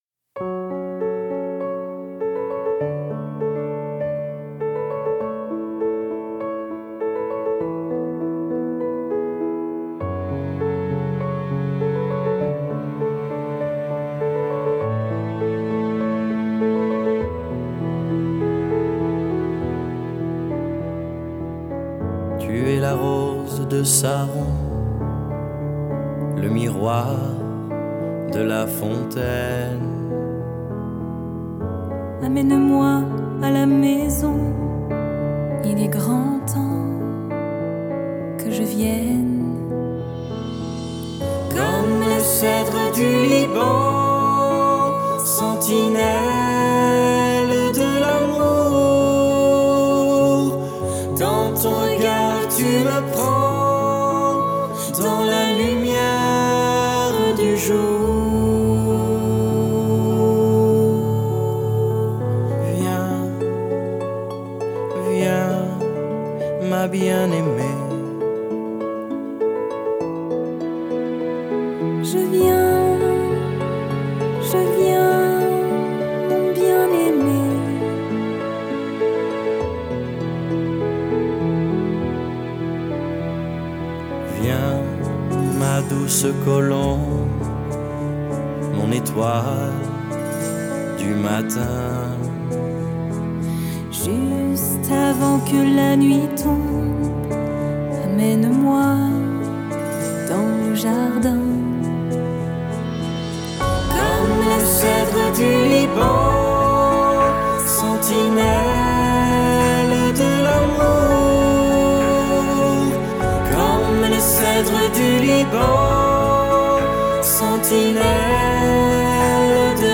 CHANT: ENREGISTREMENT EN STUDIO